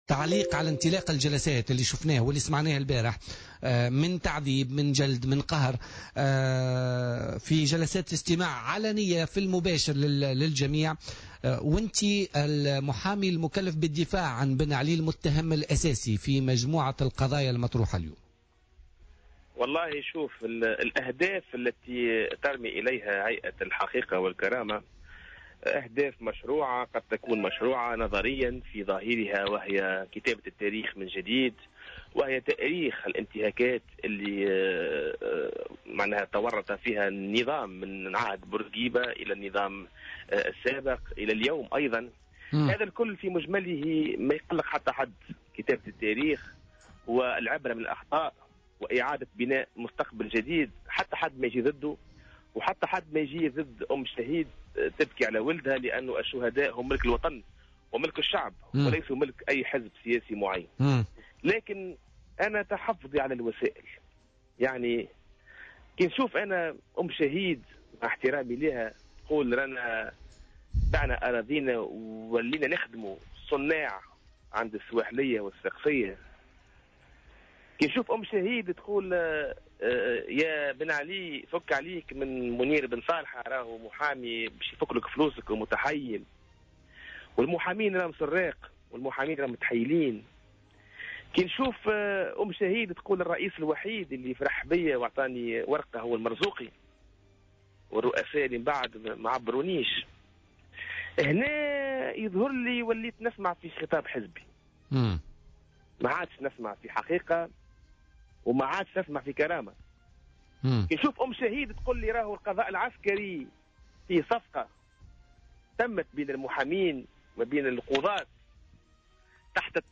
مداخلة له اليوم في برنامج "بوليتيكا"